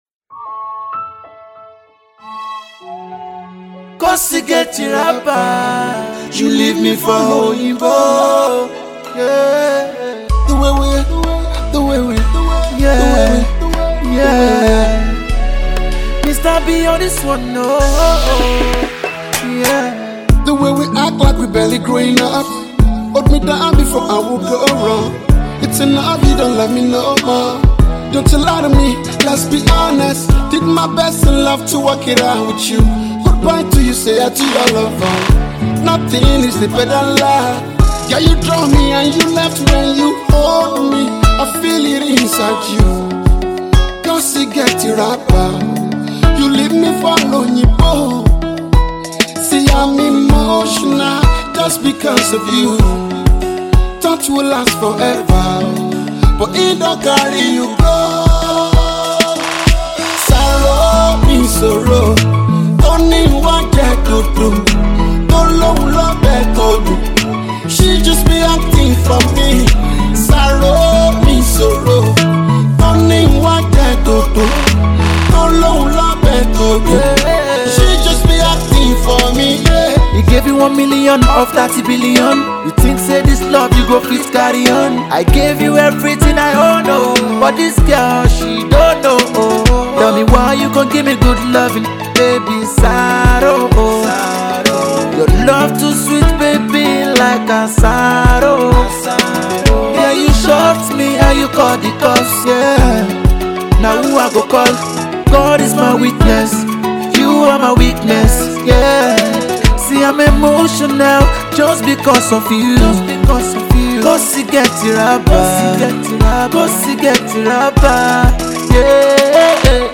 The vocals and song writing is certainly a thumbs up.